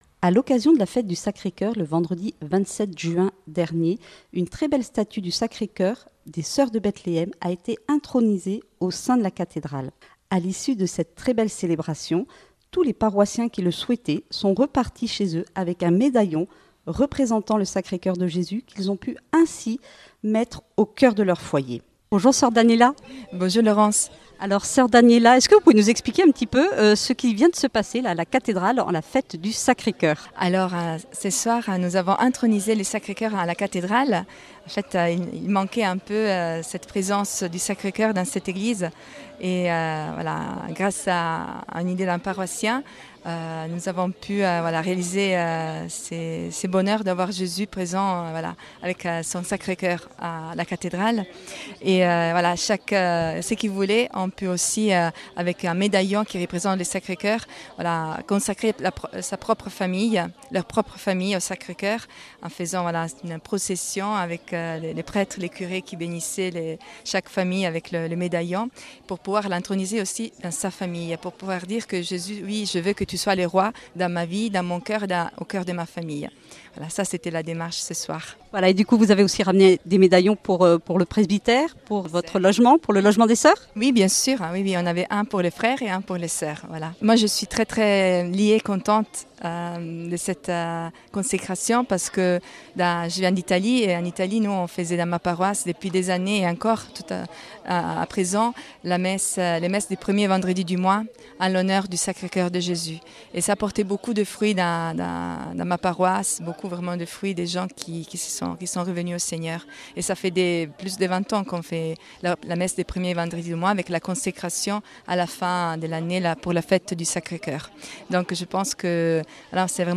A l’occasion de la fête du Sacré Cœur, une statue des sœurs de Bethléhem a été intronisée dans la chapelle latérale de la cathédrale.